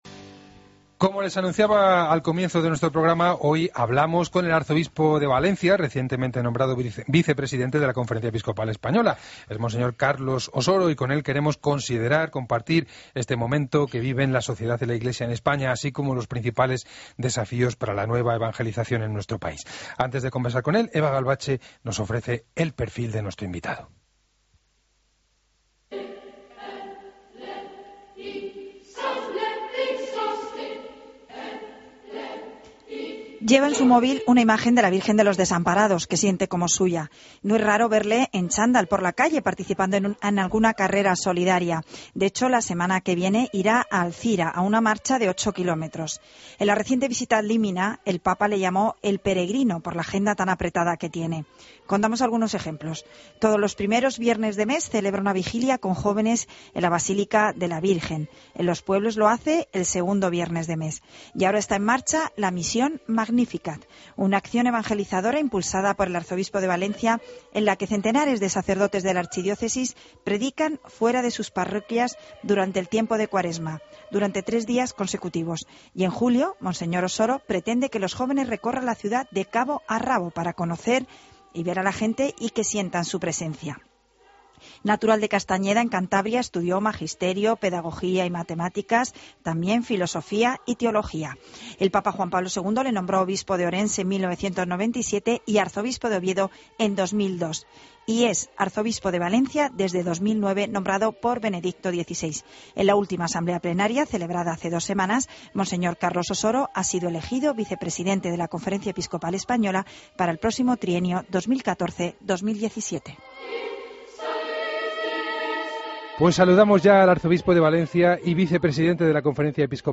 Escucha la entrevista completa a monseñor Carlos Osoro en 'El Espejo' de COPE